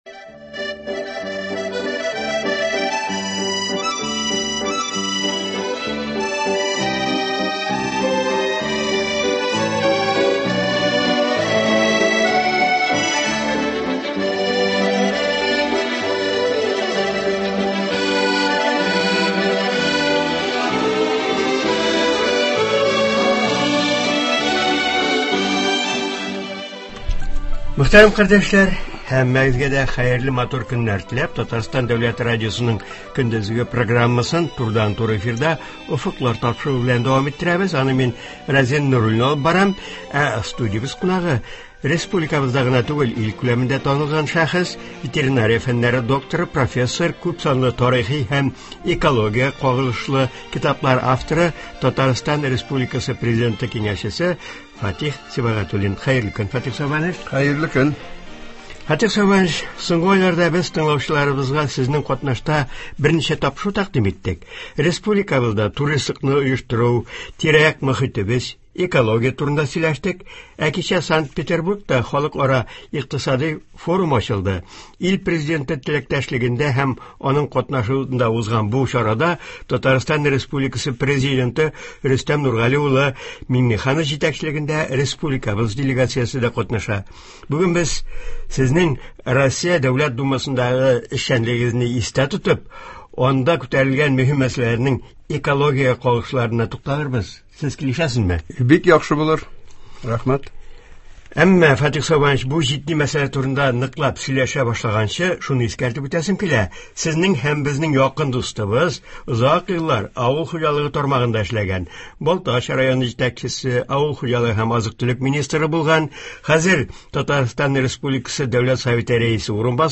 Җәйге чорда мохитебезне тәртипкә китерү, көнкүреш калдыкларыннан чистарту буенча республикабызда төрле чаралар үткәрелә. Аларның кайберләре турында Татарстан республикасы Президенты киңәшчесе, профессор, күпсанлы тарихи китаплар авторы Фатих Сибагатуллин сөйләячәк, тыңлаучылар сорауларына җавап бирәчәк.